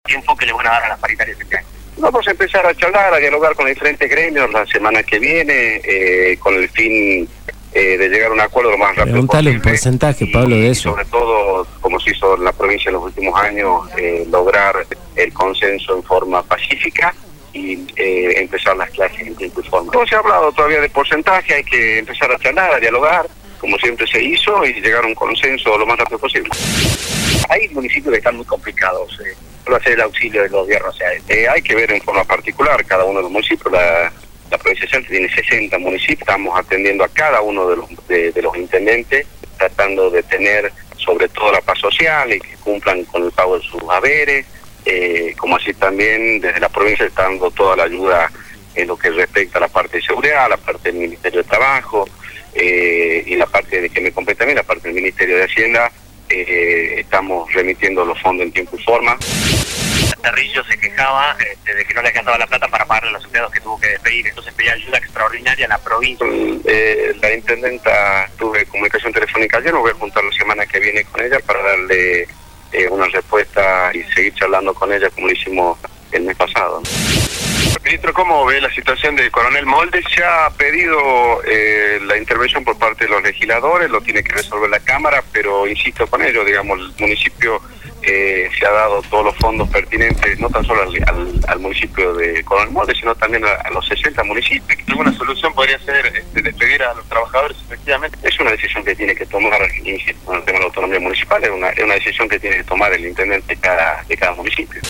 GOMEZA-RUDA-DE-PRENSA.mp3